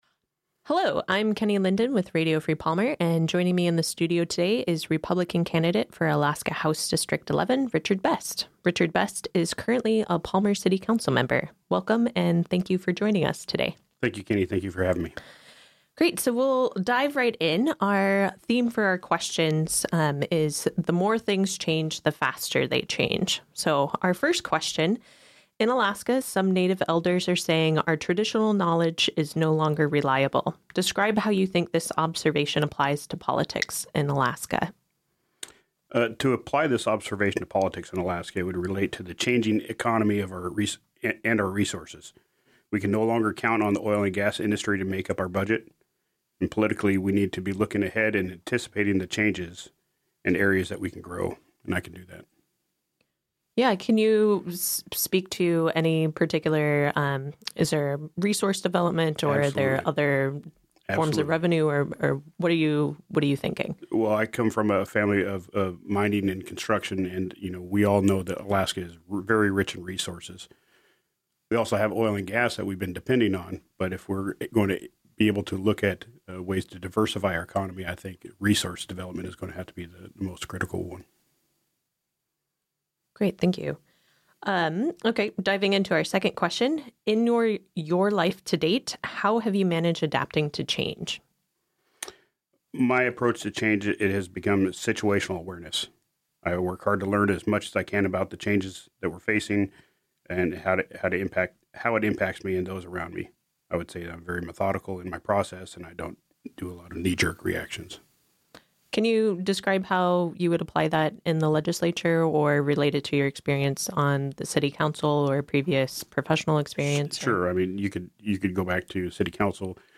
2016 Alaska August Primary Interviews
The recorded interviews were broadcast on Radiofreepalmer on Monday and Tuesday, August 8 and 9.